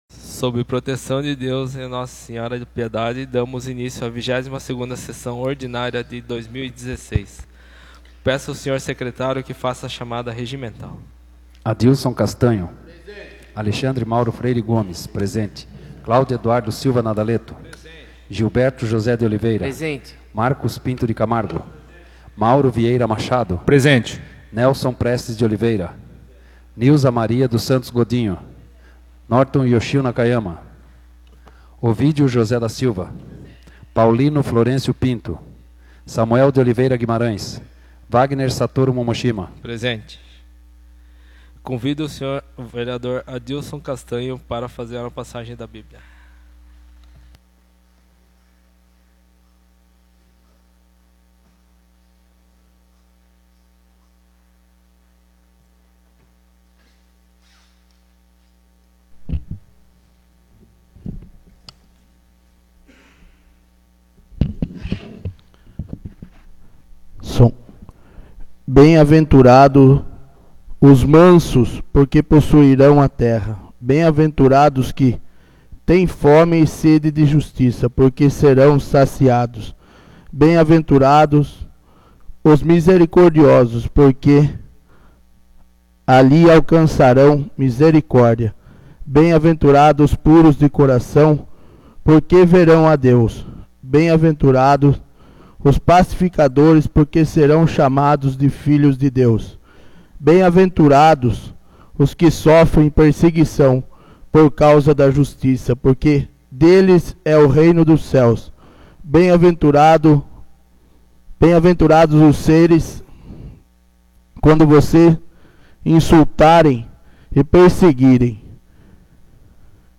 22ª Sessão Ordinária de 2016